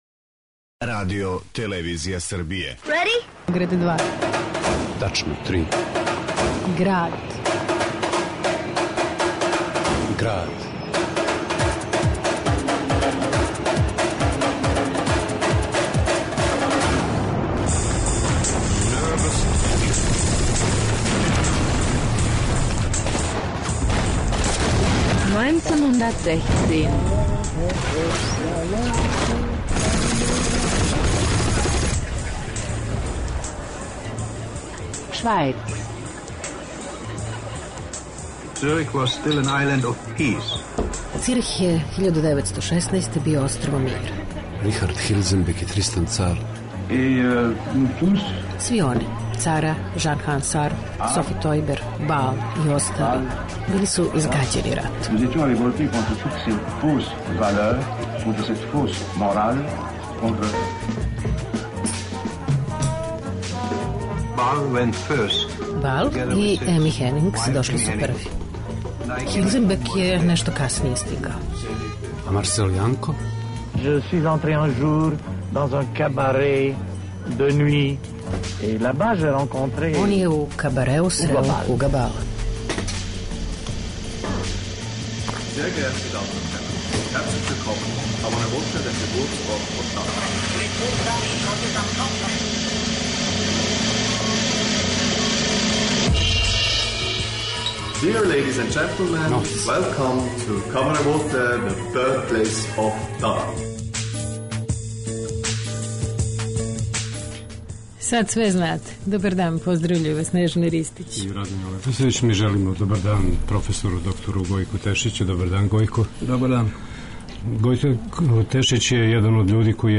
уз архивске снимке